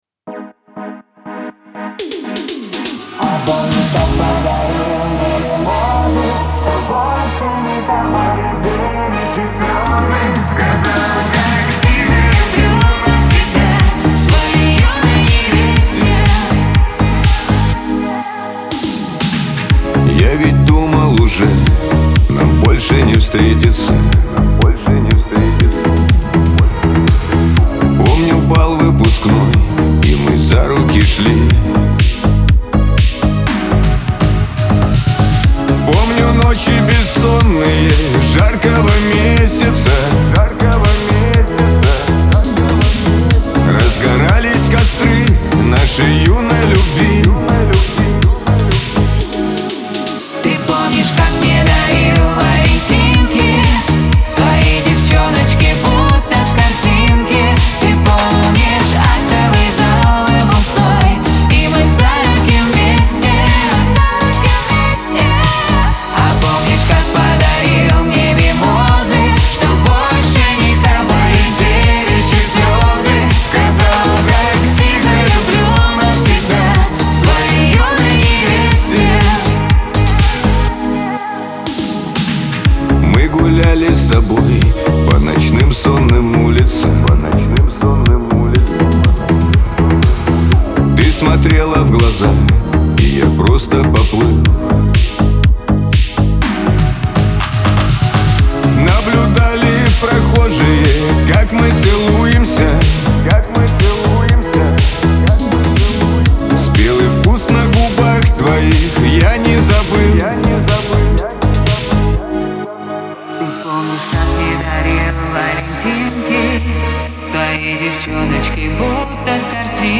pop
дуэт